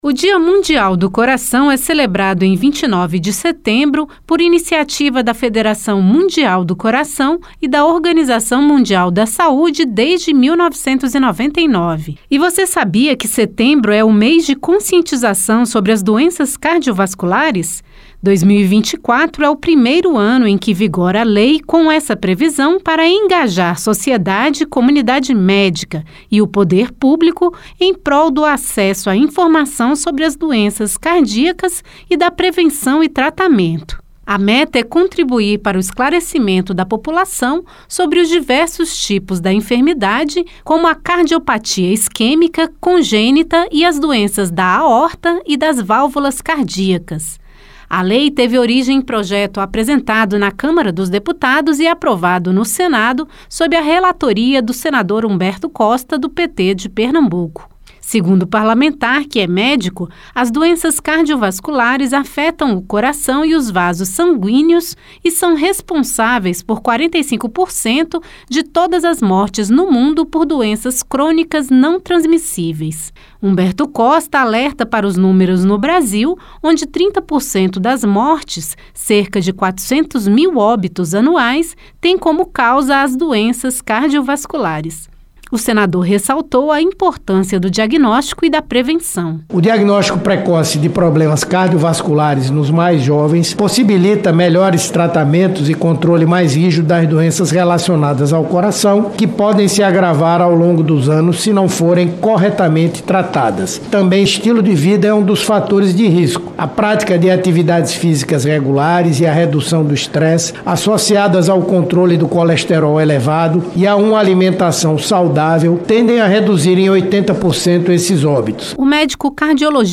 A proposta que deu origem à legislação foi relatada pelo médico e senador Humberto Costa (PT-PE). Ele ressaltou a importância do diagnóstico precoce e de bons hábitos de saúde como fatores de prevenção.